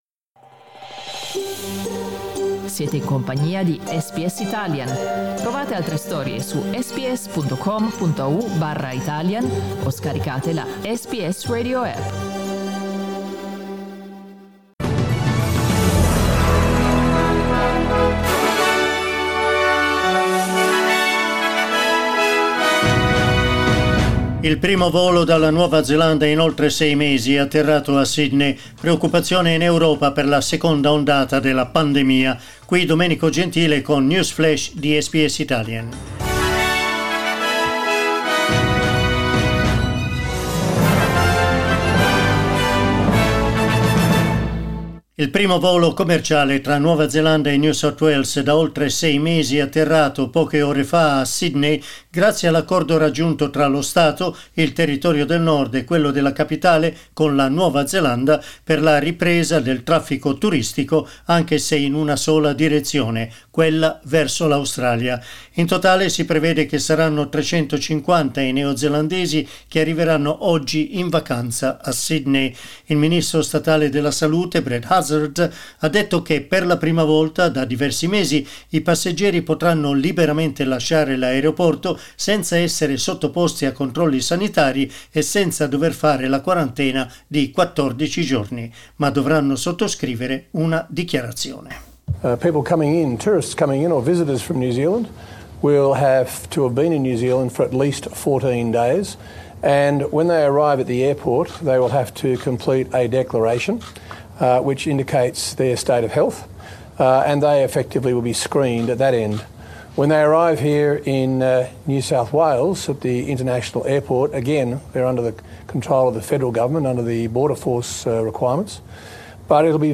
Our news update in Italian